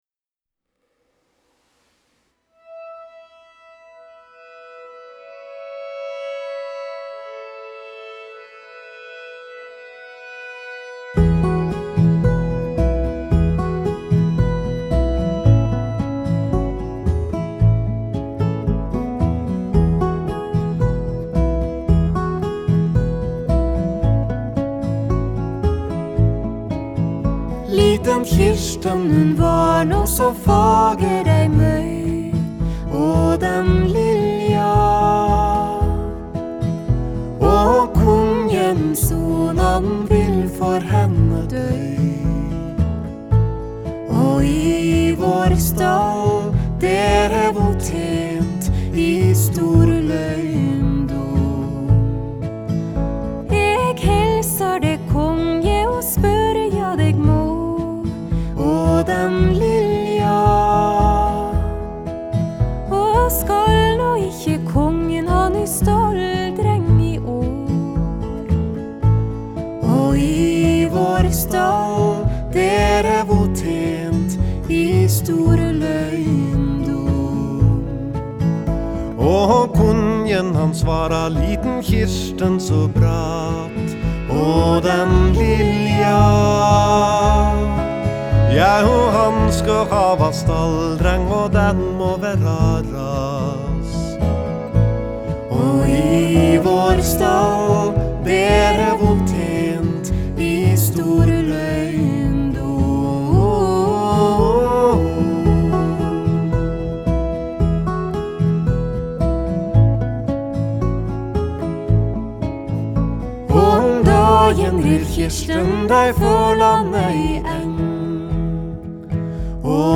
Genre: Folk-Rock, Folk-Pop, Folk
vocals, Guitar, banjo, harmonica, percussion, accordion